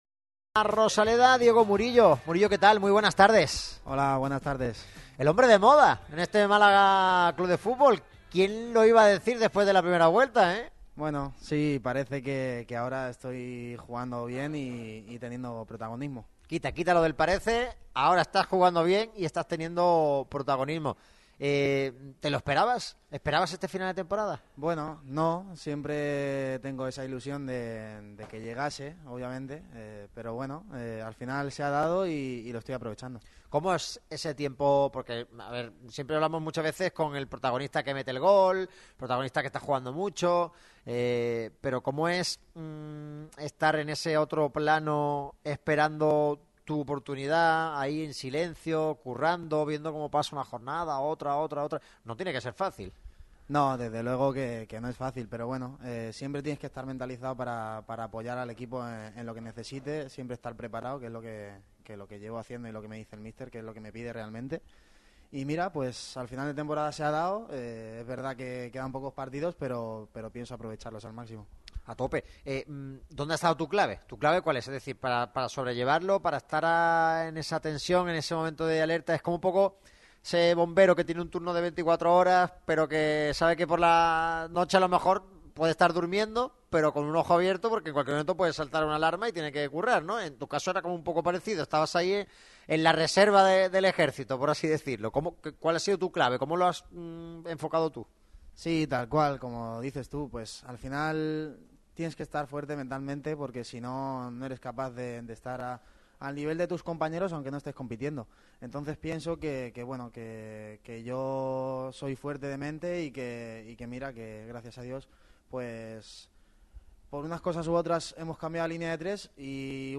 Entrevistón hoy en Radio MARCA Málaga.